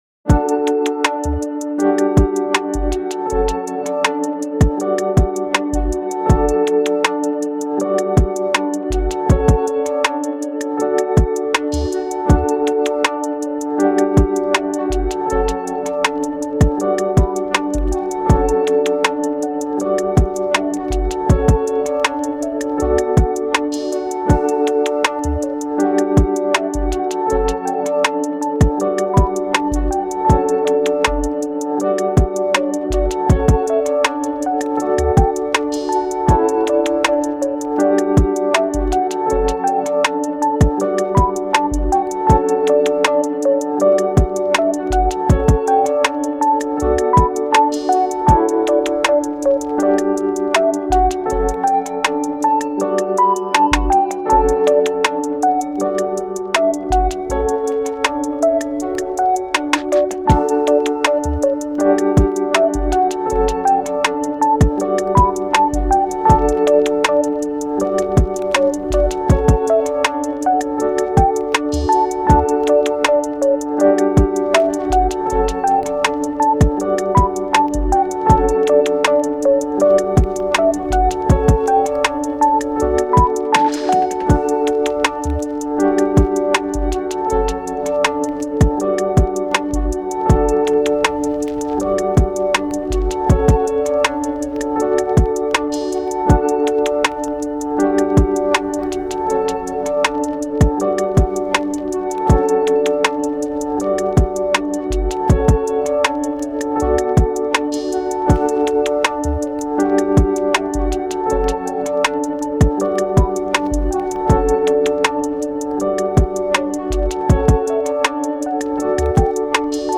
エレクトロ チル・穏やか フリーBGM
ローファイ , 夜景 , 静かな場所